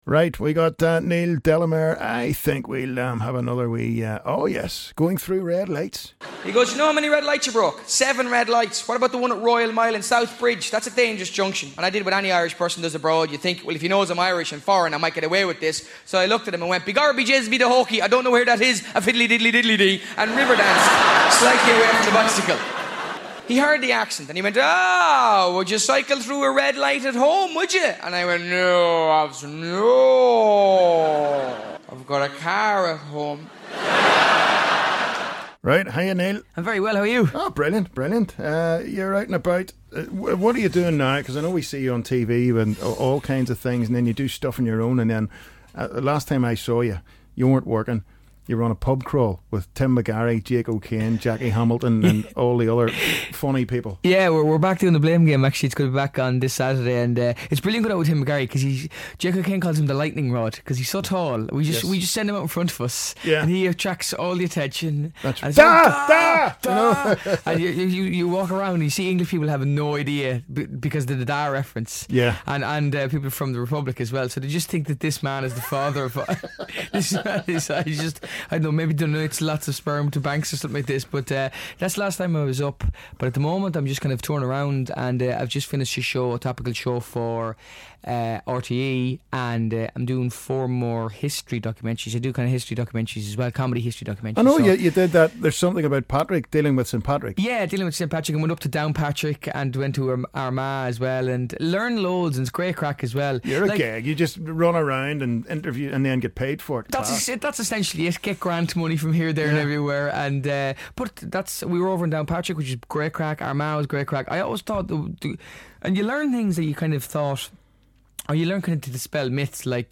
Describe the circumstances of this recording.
live in the U105 studio